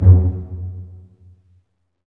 Index of /90_sSampleCDs/Roland LCDP13 String Sections/STR_Vcs Marc&Piz/STR_Vcs Pz.2 amb
STR PIZZ.05R.wav